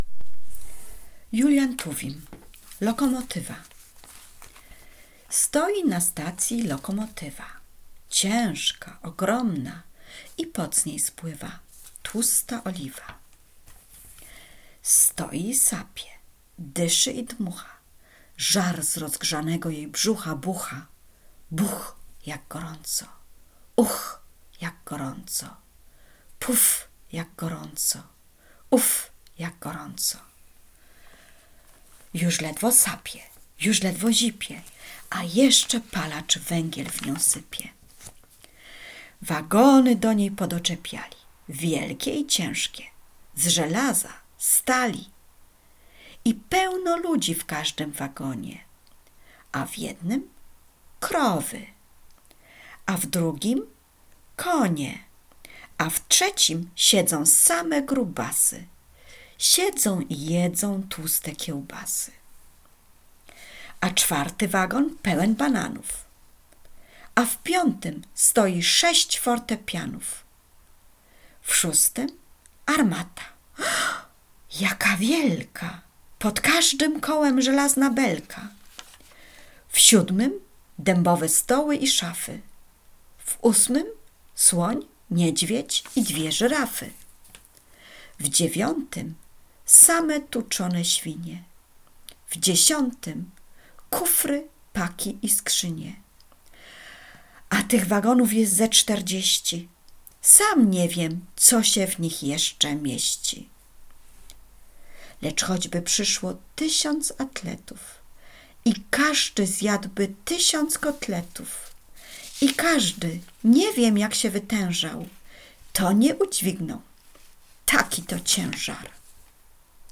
Ausschnitt aus dem sehr beliebten Kindergedicht „Lokomotywa“ von Julian Tuwim.